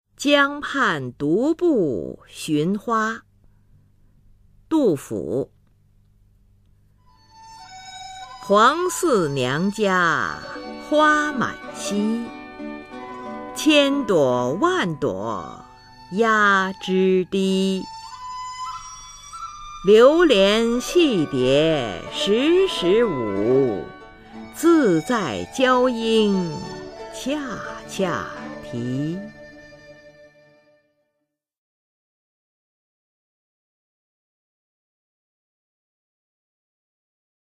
[隋唐诗词诵读]杜甫-江畔独步寻花（女） 唐诗朗诵